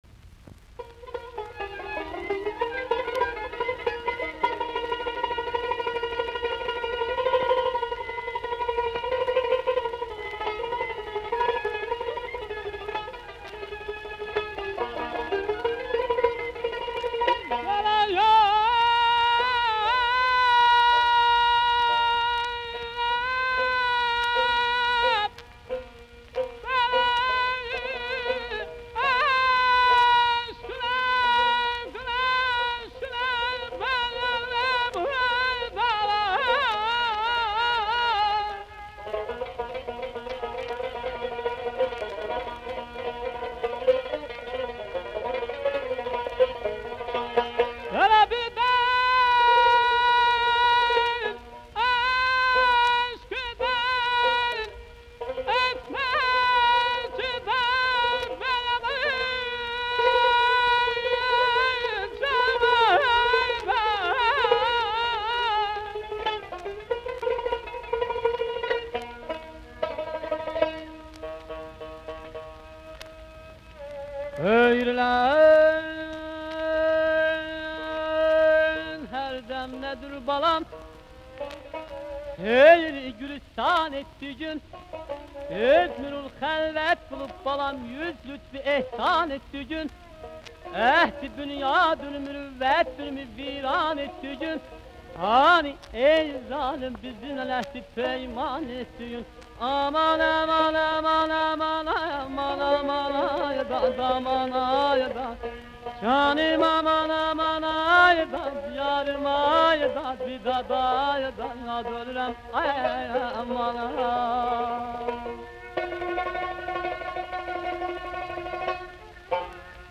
From Azerbaijan. Folk song with tar, tambur, doira.